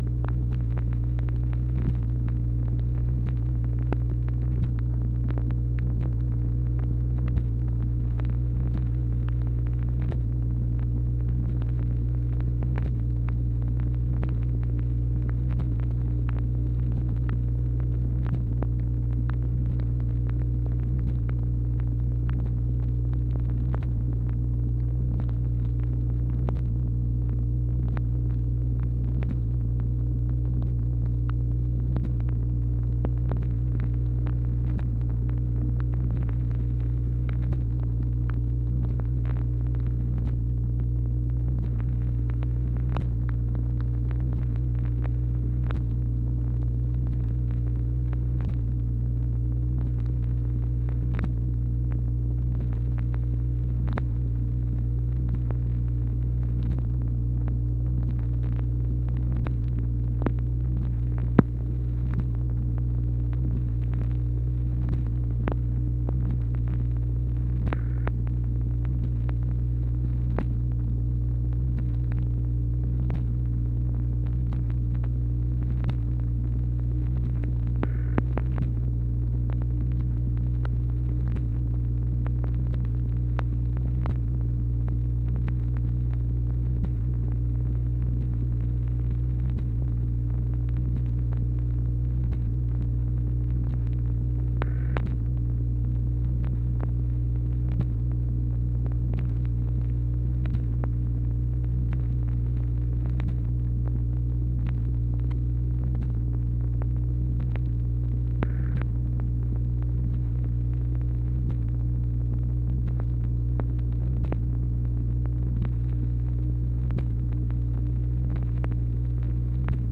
MACHINE NOISE, January 5, 1964
Secret White House Tapes | Lyndon B. Johnson Presidency